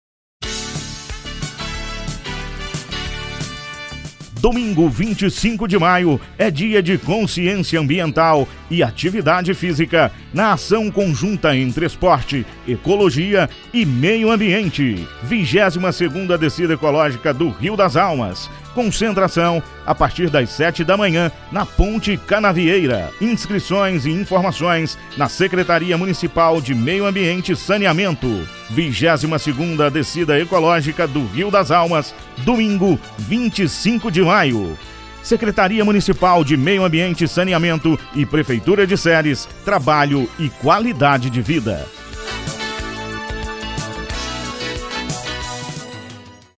Spot rádio Link do audio